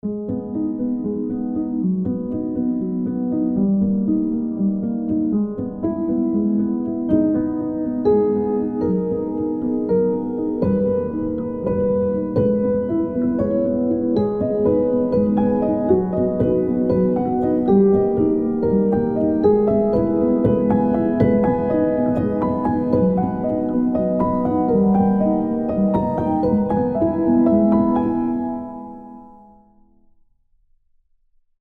Soft Piano Classical Piano